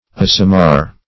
Search Result for " assamar" : The Collaborative International Dictionary of English v.0.48: Assamar \As"sa*mar\, n. [L. assare to roast + amarus, bitter.]